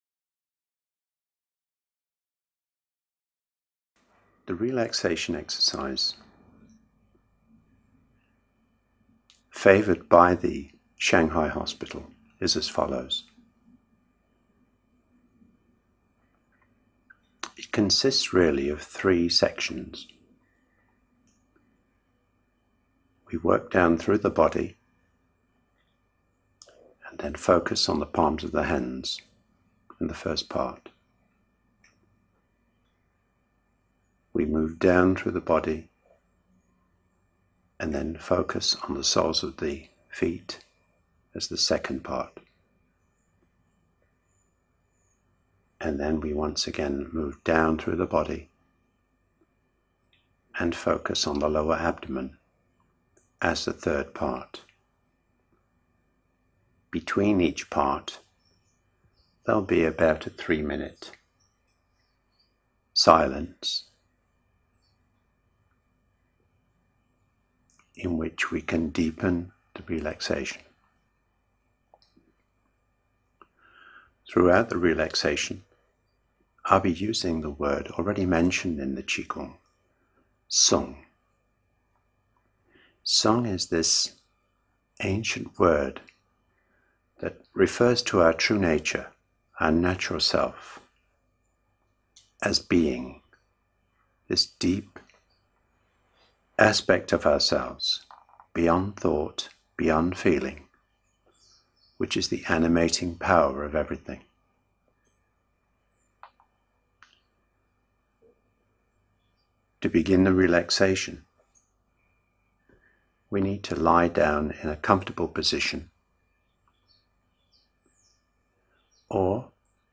Relaxation
Relaxtion-Routine.mp3